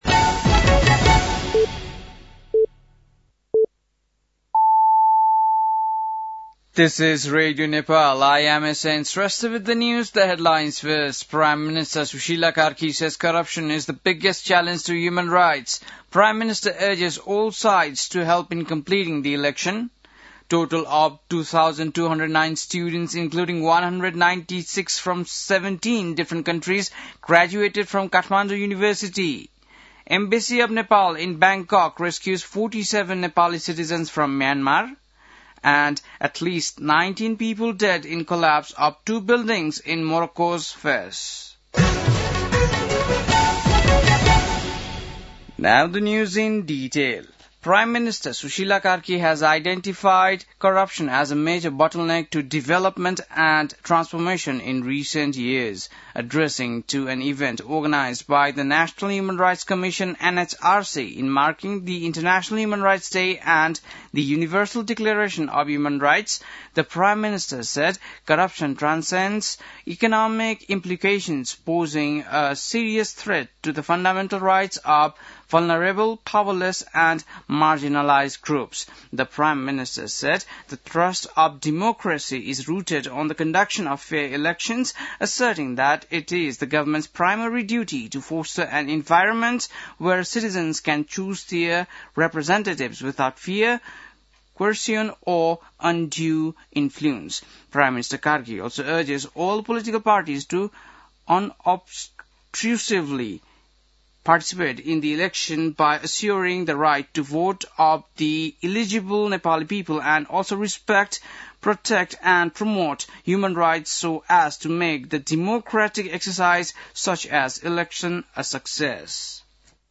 बेलुकी ८ बजेको अङ्ग्रेजी समाचार : २४ मंसिर , २०८२
8-pm-english-news-8-24.mp3